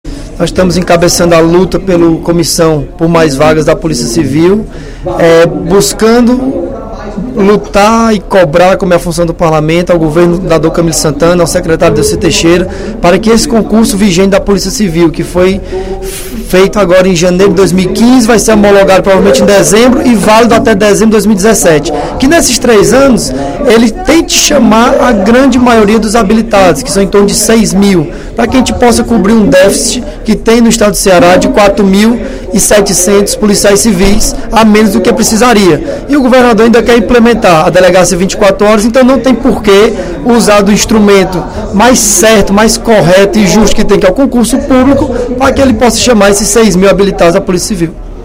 O deputado Bruno Gonçalves (PEN) destacou, durante o primeiro expediente da sessão plenária da Assembleia Legislativa desta quarta-feira (24/06), o encontro com representantes do Movimento por Mais Vagas.